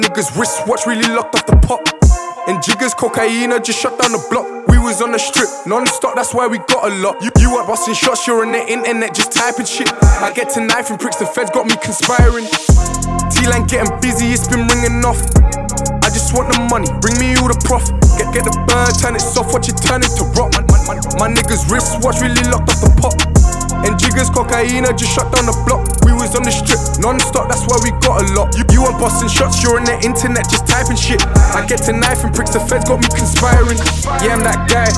Жанр: Хип-Хоп / Рэп / Электроника